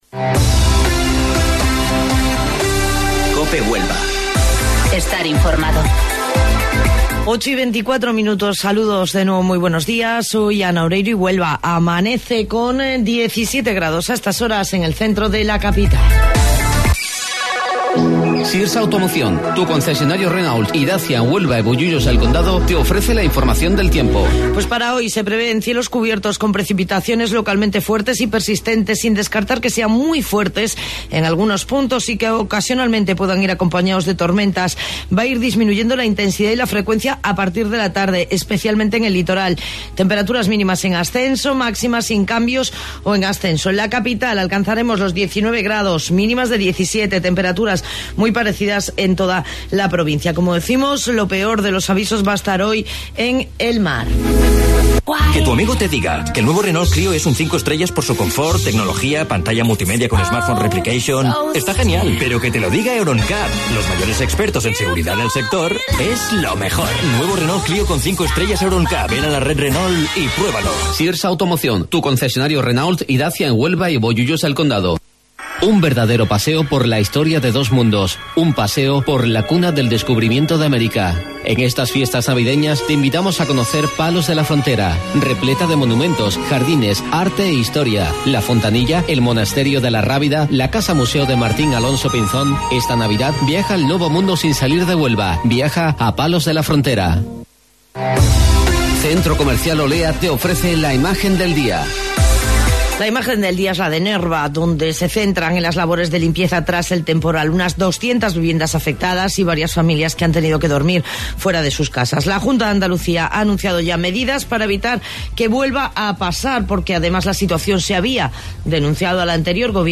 AUDIO: Informativo Local 08:25 del 20 de Diciembre